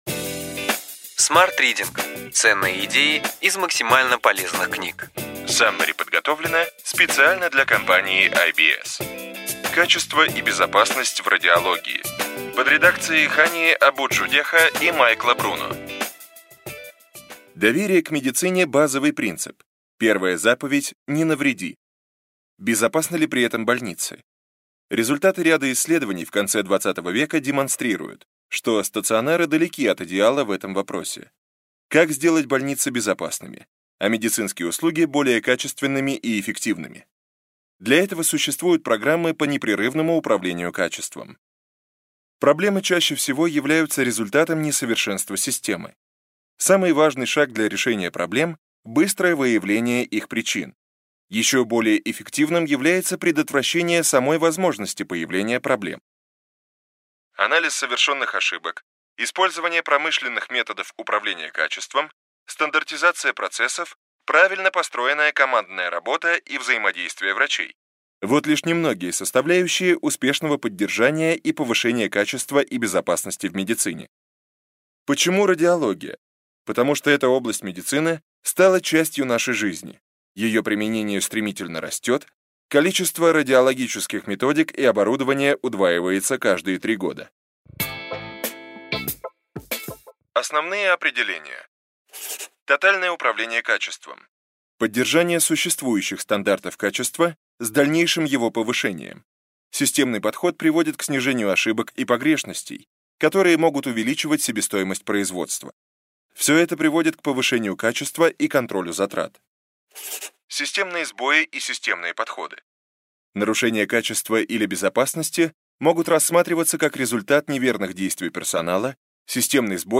Аудиокнига Ключевые идеи книги: Качество и безопасность в радиологии | Библиотека аудиокниг